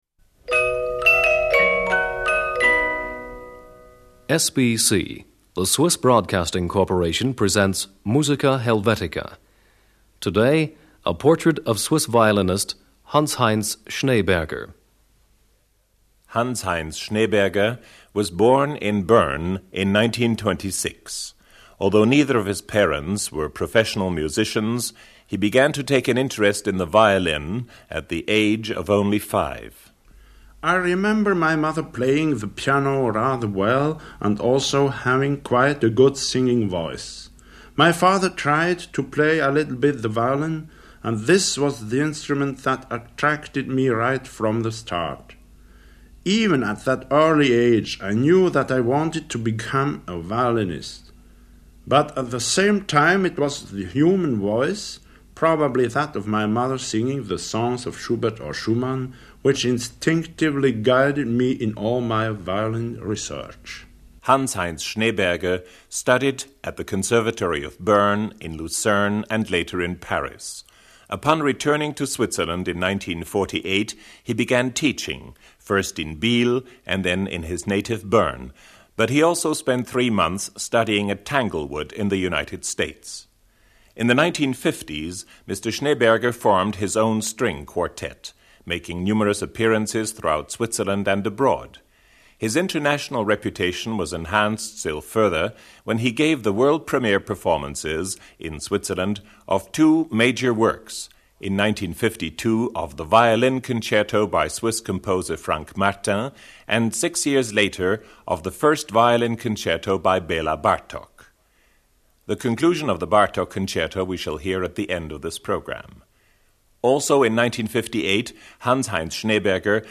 conductor Live recording at Radio Geneva in 1968 2.